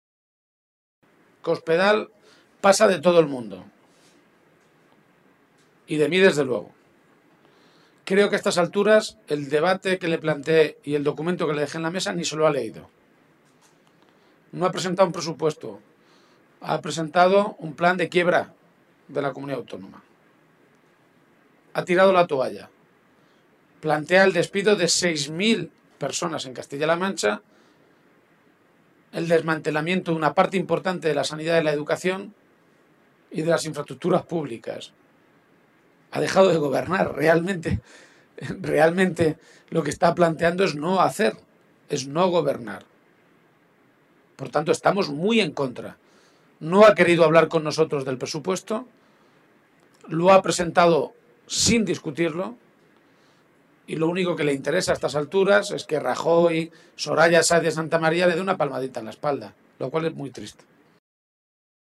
Poco antes del inicio de esa reunión, el secretario general regional, Emiliano García-Page, ha comparecido ante los medios de comunicación para anunciar la aprobación de una resolución que ha llamado “La Resolución de Cuenca”.